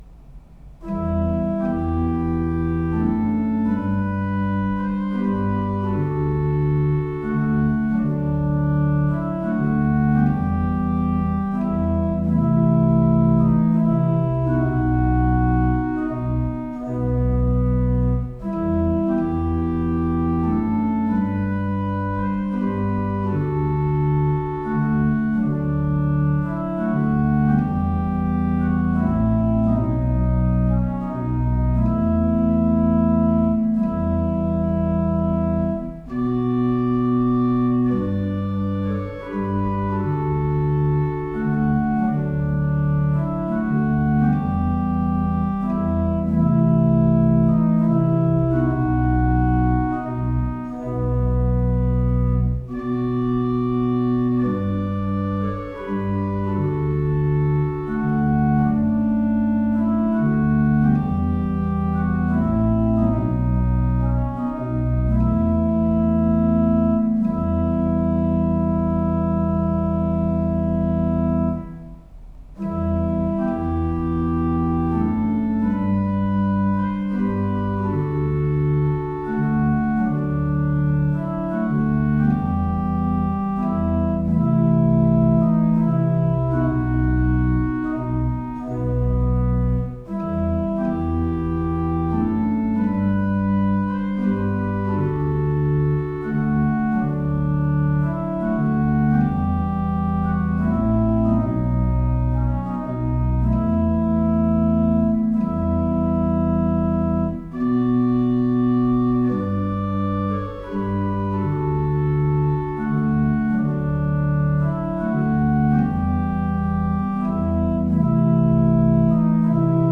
Audio Recordings (Organ)
WS712-midquality-mono.mp3